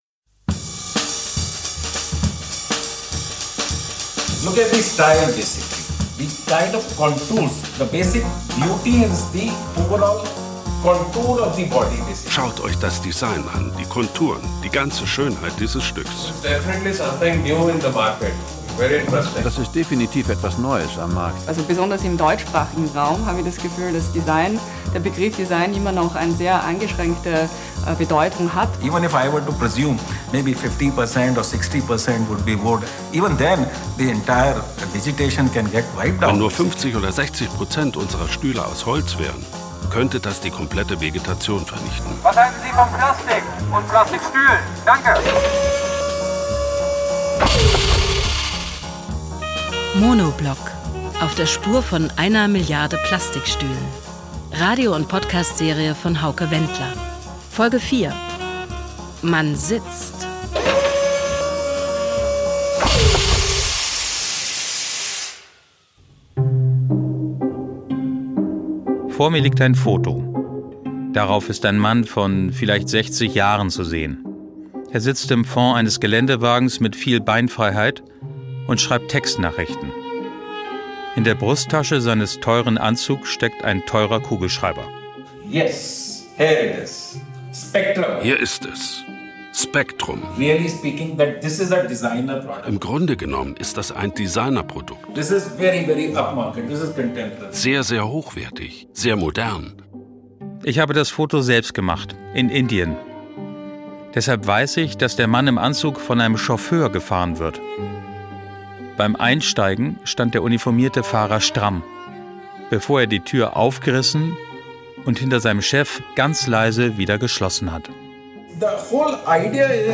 Beschreibung vor 3 Jahren Indien ist ein starker Produzent von Plastikstühlen. Die Hauptstadt: laut, eng und voller Eindrücke.